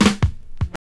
33DR.BREAK.wav